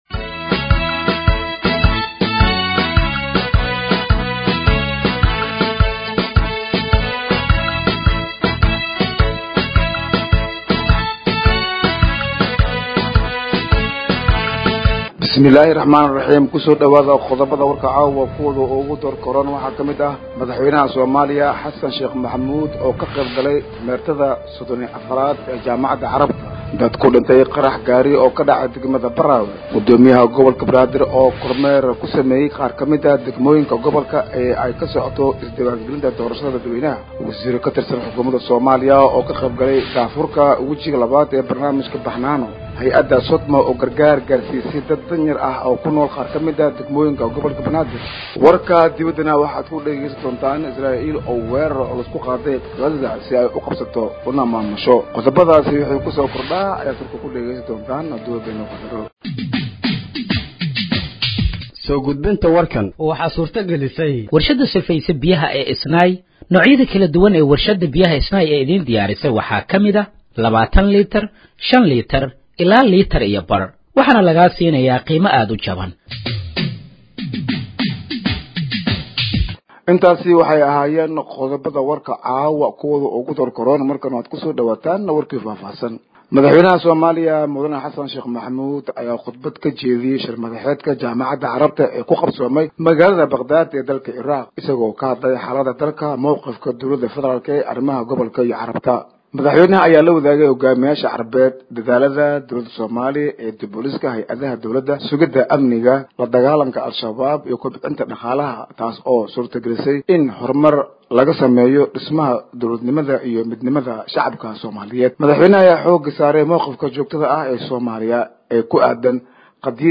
Dhageeyso Warka Habeenimo ee Radiojowhar 17/05/2025